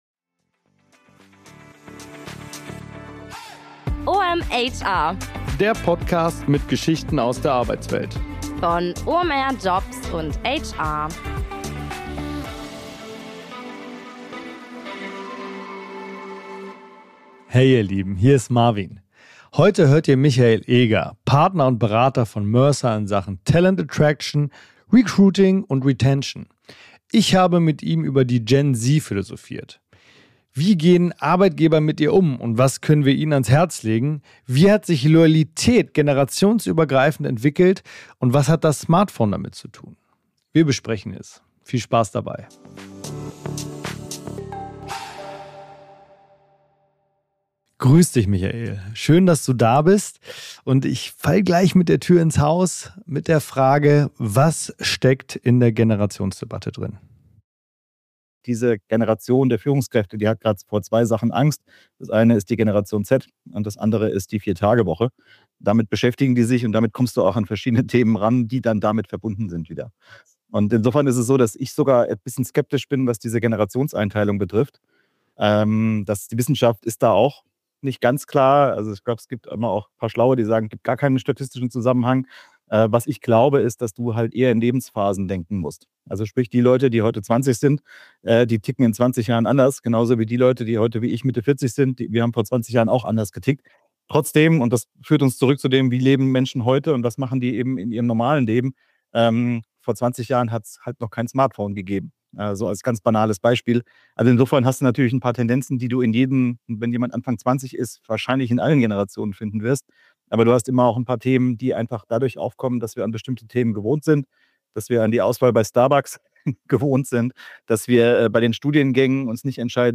Welche Werte sind der Gen Z wichtig – und warum ist ihre Loyalität nicht geringer, sondern einfach anders? Es geht um emotionale Bindung statt Langzeitverträge, um das Bedürfnis, wertgeschätzt zu werden und die Frage, wie Unternehmen heute Vertrauen aufbauen können. Ein Interview über neue Arbeitsrealitäten, alte Denkmuster und warum Treue nicht zwingend an Jahrzehnte gebunden ist.